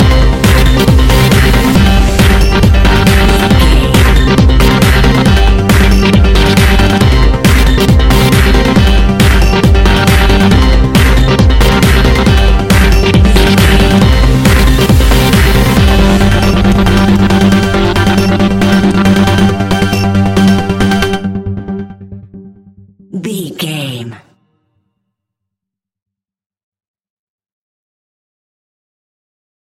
Aeolian/Minor
Fast
energetic
uplifting
hypnotic
drum machine
piano
synthesiser
acid house
electronic
uptempo
synth leads
synth bass